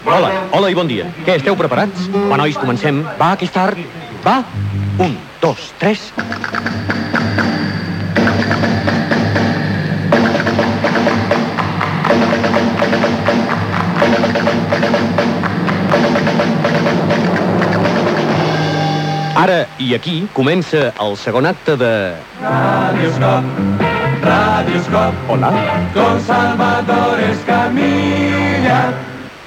Inici de la segona hora del programa i indicatiu cantat del programa
Entreteniment